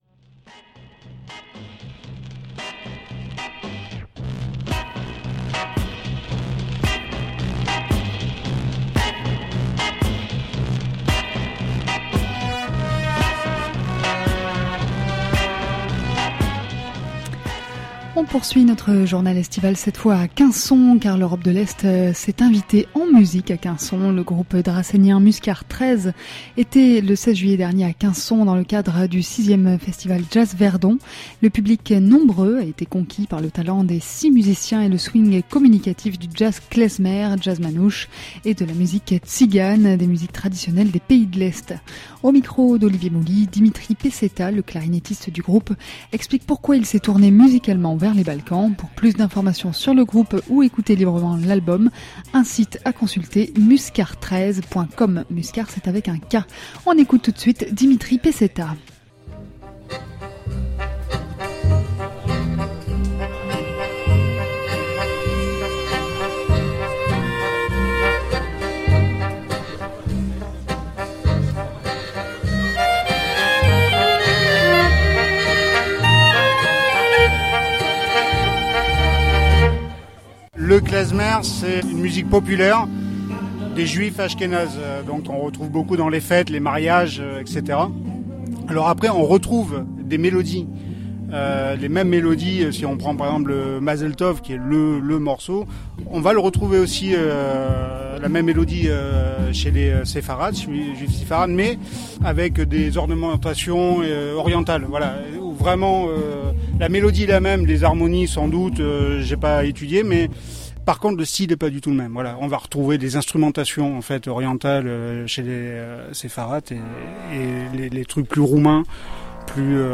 Le groupe dracénien Muskar XIII était en juillet dernier à Quinson dans le cadre du 6ème festival Jazz Verdon. Le public nombreux a été conquis par le talent des six musiciens et le swing communicatif du Jazz Klezmer, du jazz manouche, de la musique tzigane, ou des musiques traditionnelles des pays d’Europe de l’Est.